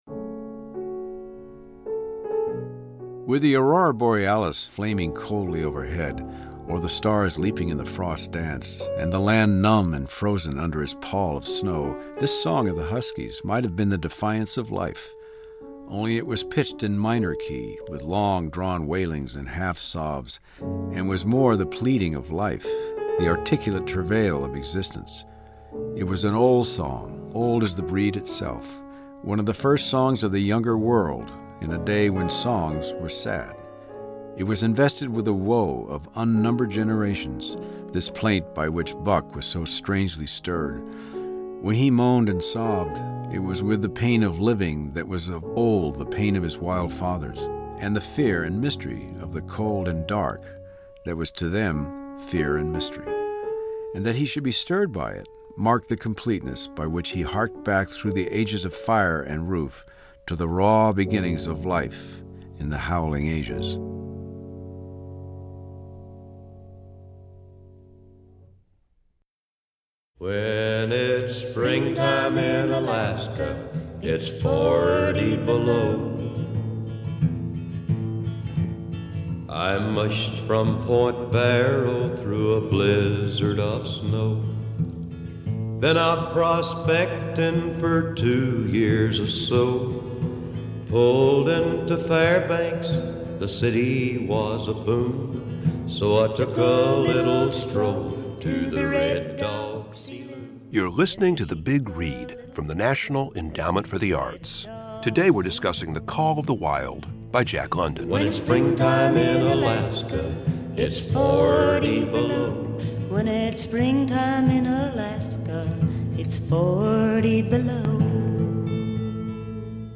Music credit: Excerpt of "When It's Springtime in Alaska (it's Forty Below) written by Tillman Frank and Johnny Horton and performed by Johnny Cash, used courtesy of Sony Music Entertainment and by permission of Universal Cedarwood Publishing (BMI).